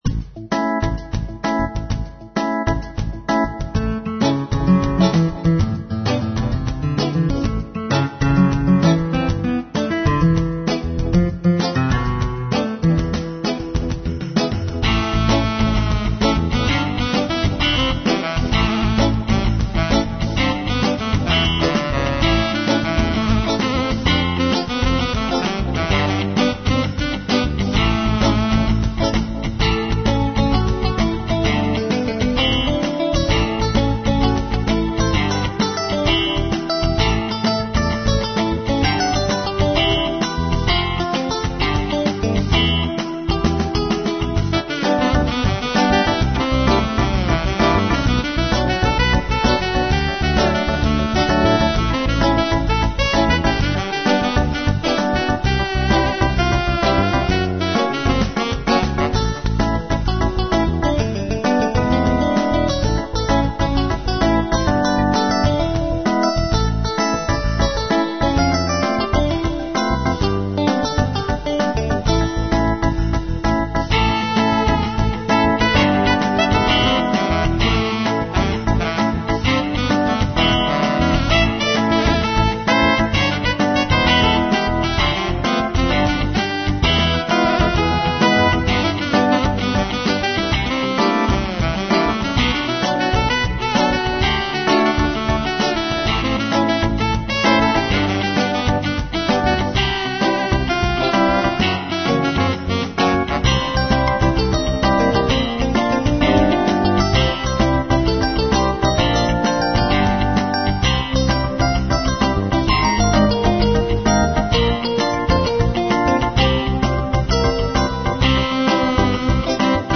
Playful Shuffle Rock with Synth Saxophone lead